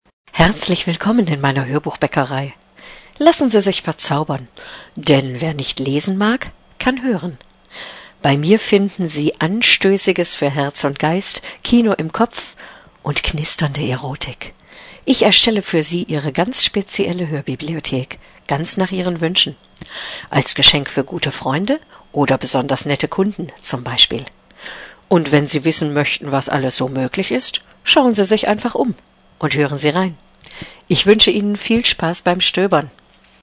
deutsche Sprecherin.
norddeutsch
german voice over artist